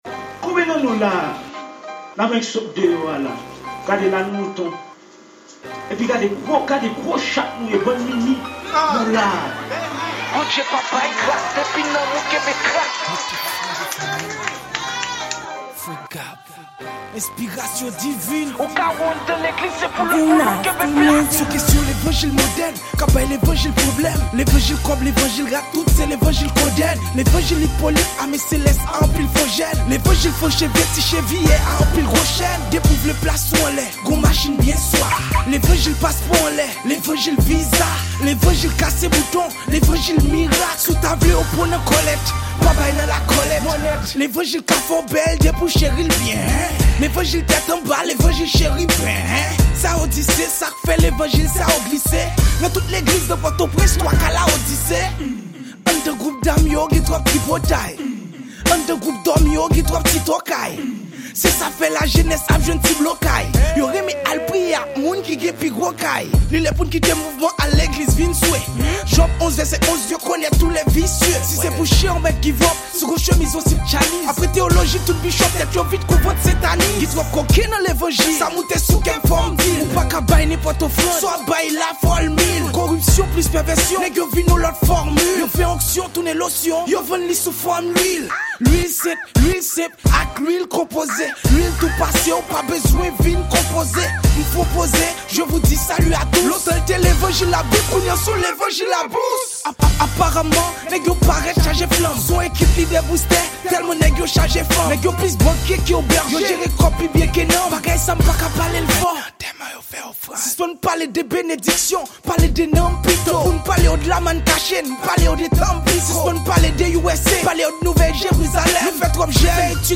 Genre: Rap Gospel.